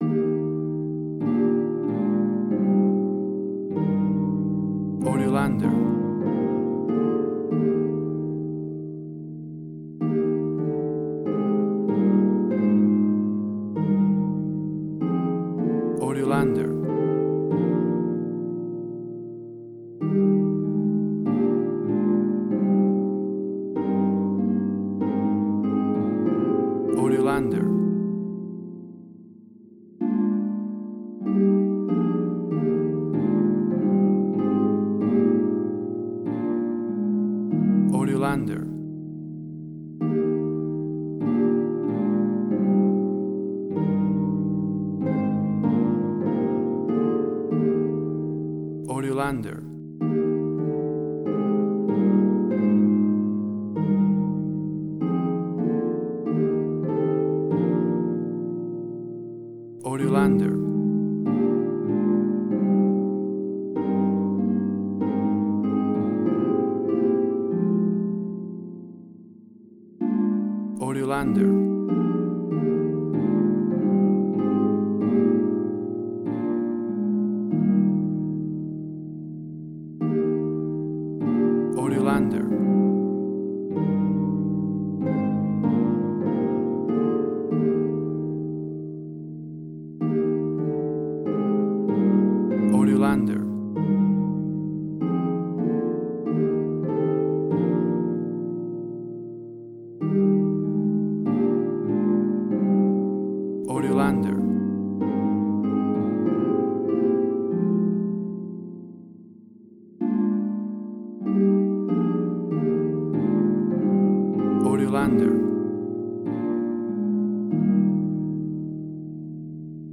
A traditional church organ version of this classic hymn.
Tempo (BPM): 96